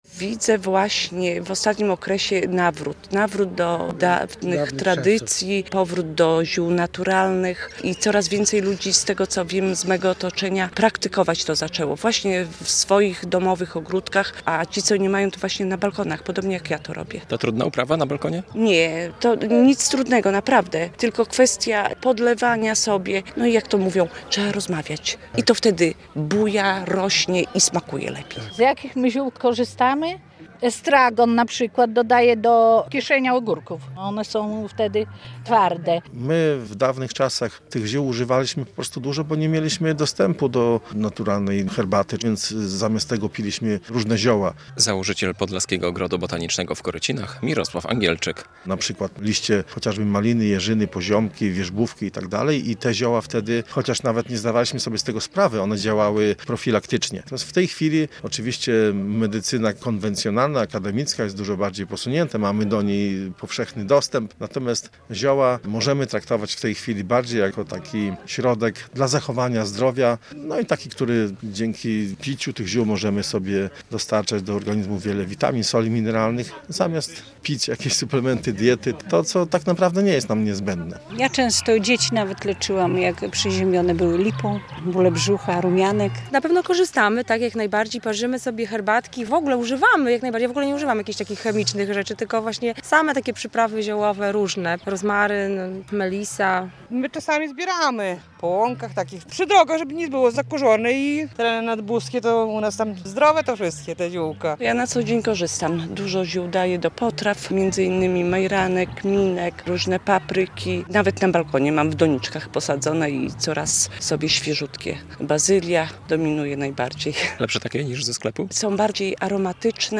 Święto ziół w Korycinach - relacja